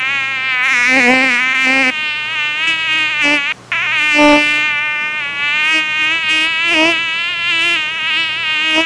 Mosquito Attack Sound Effect
Download a high-quality mosquito attack sound effect.
mosquito-attack.mp3